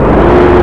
dukedoor.wav